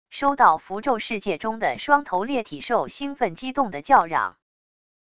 收到符咒世界中的双头裂体兽兴奋激动的叫嚷wav音频